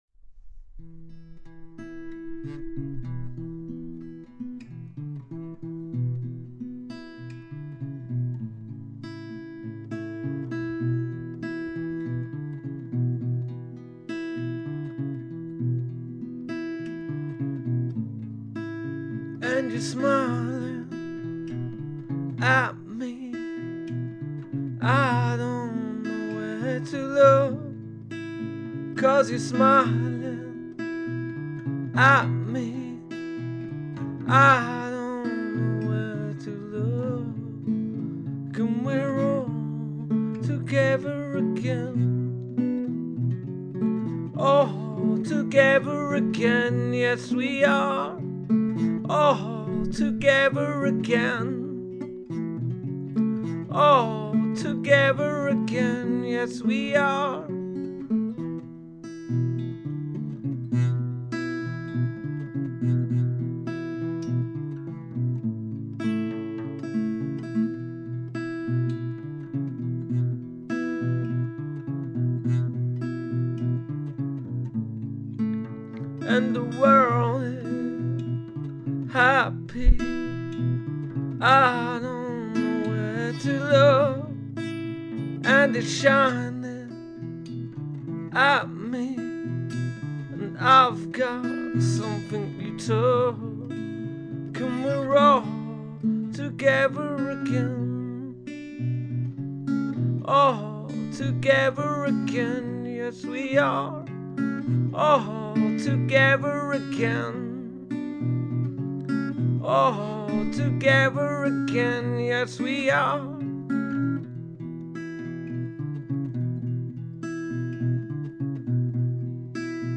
An acoustic version
using the Tascam Recorder.